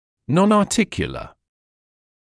[ˌnɔnɑː’tɪkjələ][ˌнонаː’тикйэлэ]несуставной